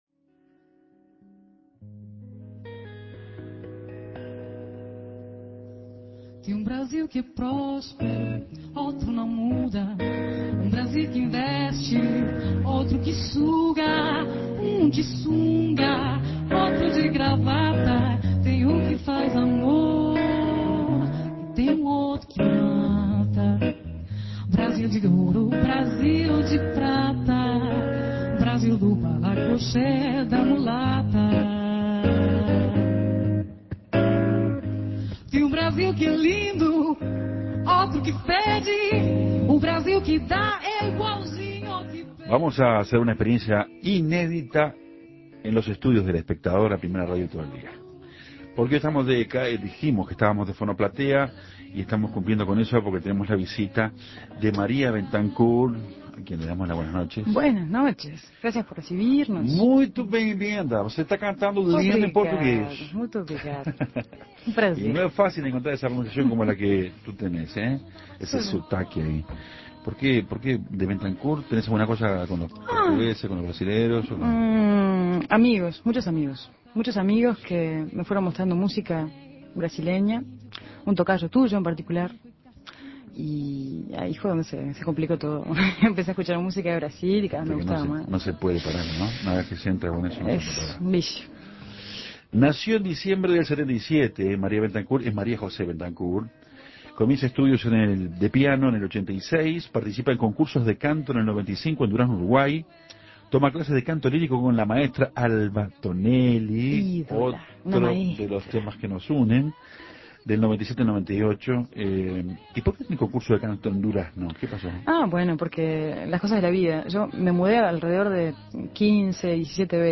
Con una voz espectacular y hablando en perfecto portugués
guitarrista